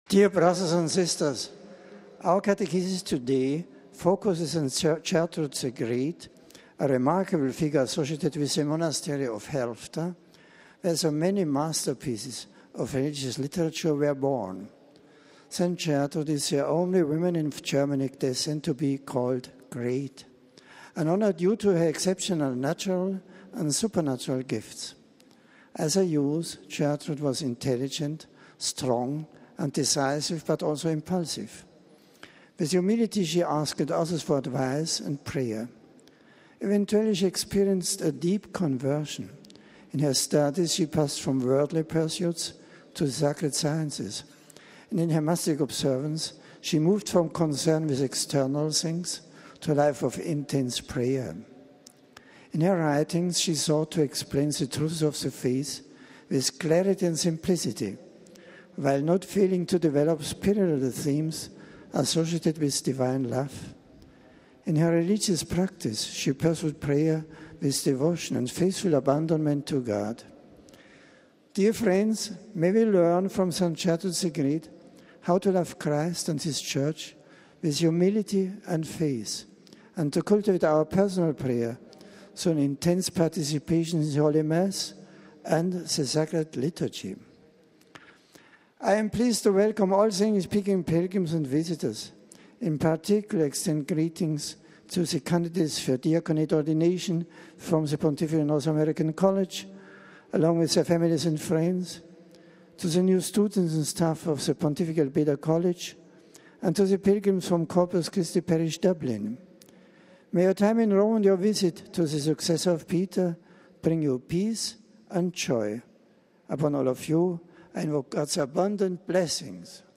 Pope stresses prayer and devotion during Weekly Audience